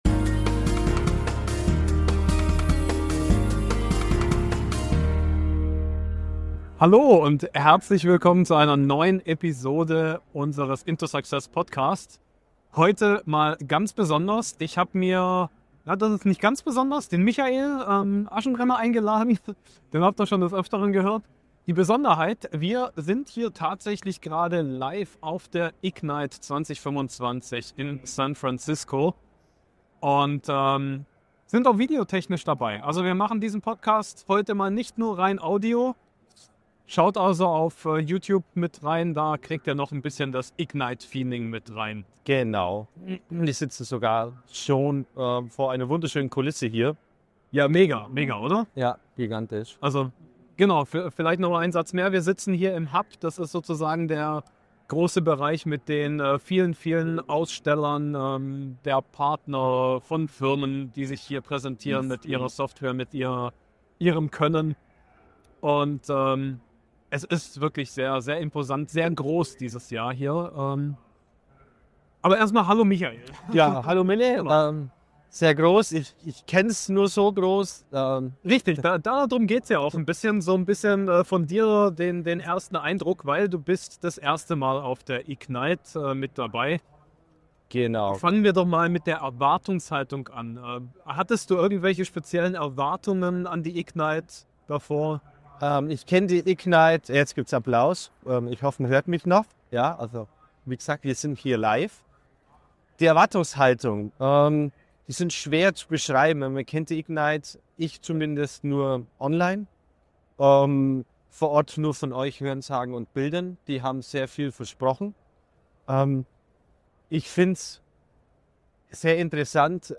Live von der Ignite 2025 – KI, Agents & Shadow AI ~ in2success Podcast